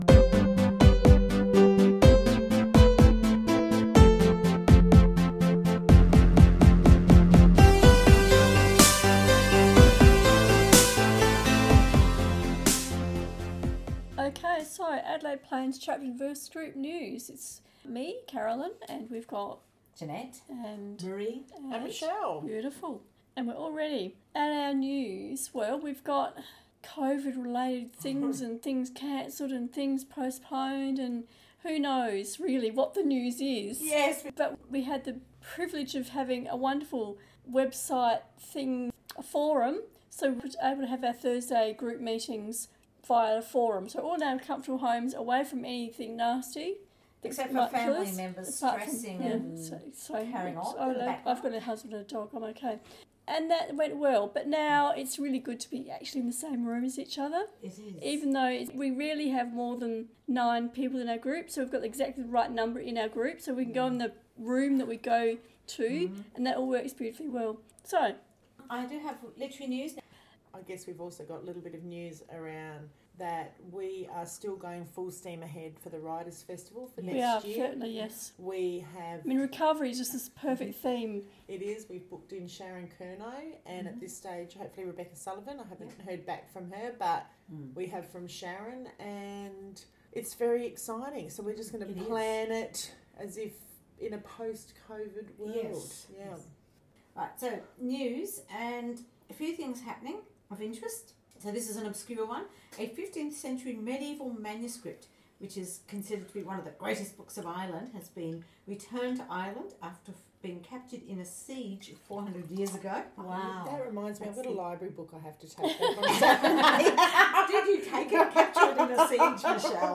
2020 Adelaide Plains Chapter and Verse monthly podcast recorded Wednesday 25 st November, Gawler South.